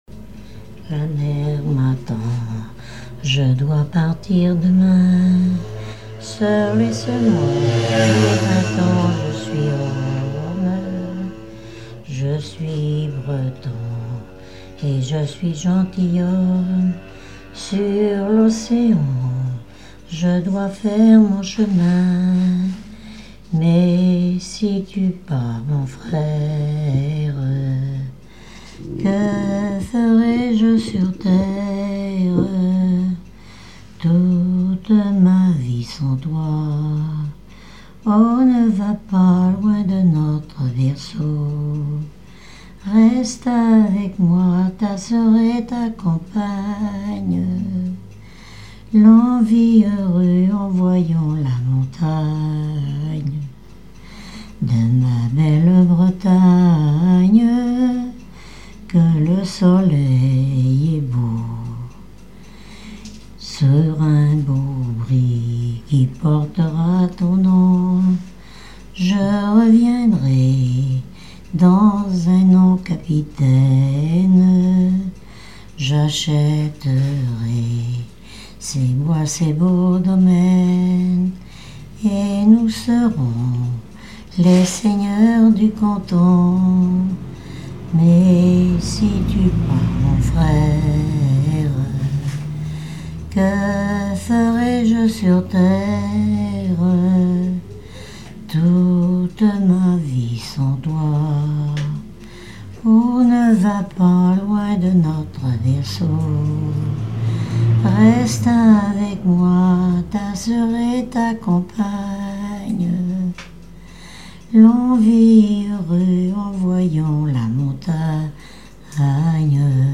Genre strophique
Recherche de chansons maritimes
Catégorie Pièce musicale inédite